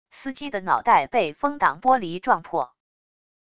司机的脑袋被风挡玻璃撞破wav音频